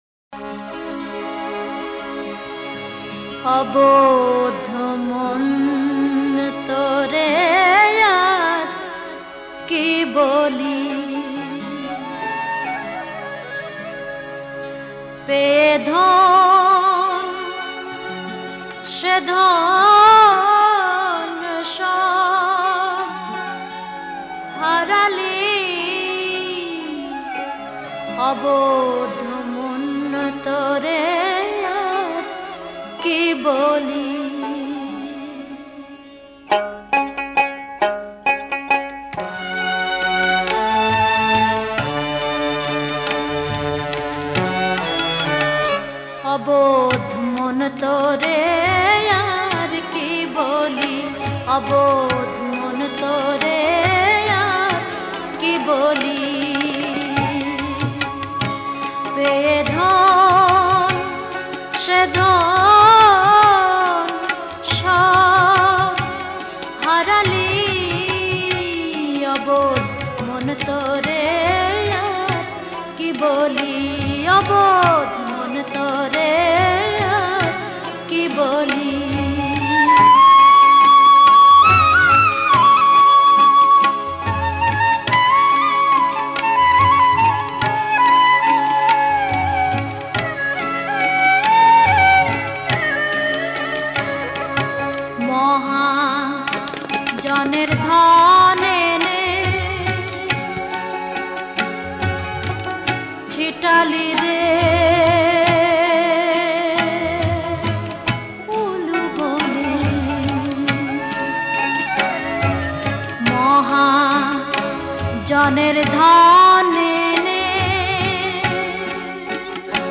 Bengali Kalam